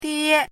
chinese-voice - 汉字语音库
die1.mp3